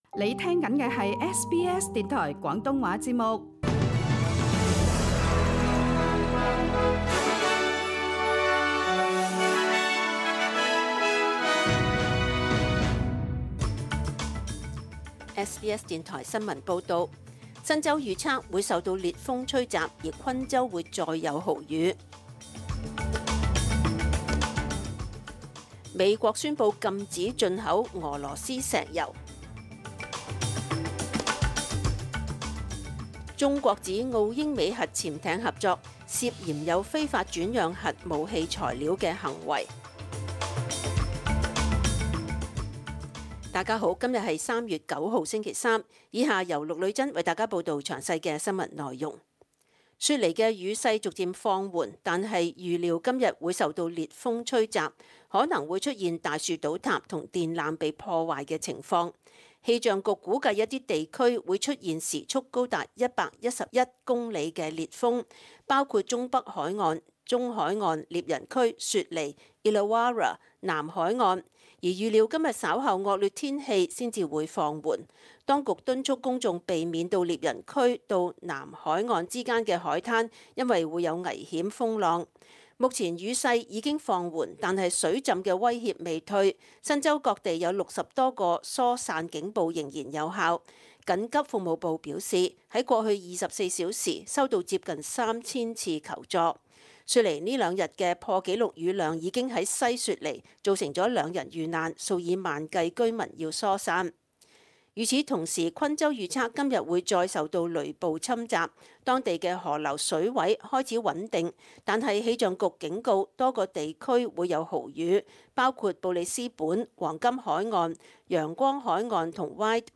SBS 廣東話節目中文新聞 Source: SBS Cantonese